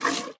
Minecraft Version Minecraft Version latest Latest Release | Latest Snapshot latest / assets / minecraft / sounds / mob / mooshroom / milk1.ogg Compare With Compare With Latest Release | Latest Snapshot
milk1.ogg